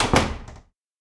Other Sound Effects